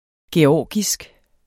georgisk adjektiv Bøjning -, -e Udtale [ geˈɒˀgisg ] Betydninger 1. fra Georgien; vedr.